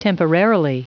Prononciation du mot : temporarily